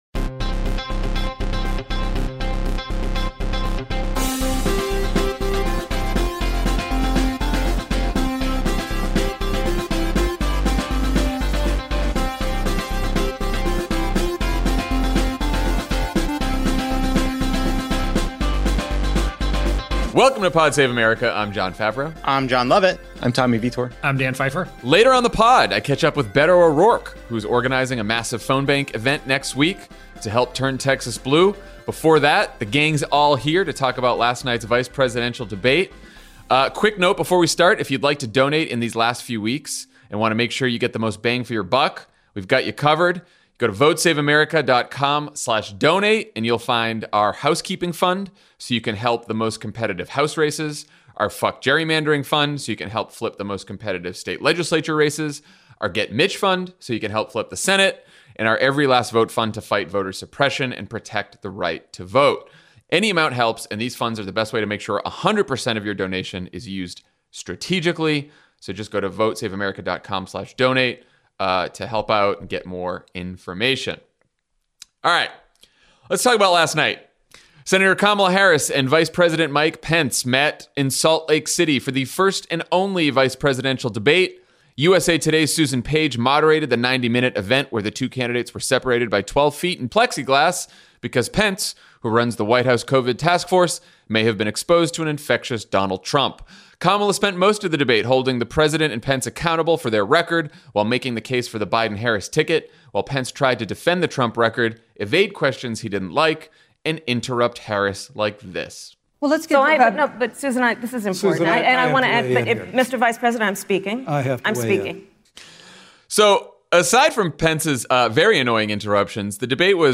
Jon, Jon, Tommy, and Dan break down the vice presidential debate between Senator Kamala Harris and Vice President Mike Pence, and discuss Donald Trump’s decision to pull out of the second presidential debate. Then Beto O’Rourke talks to Jon Favreau about his efforts to turn Texas blue through his grassroots organization, Powered by People.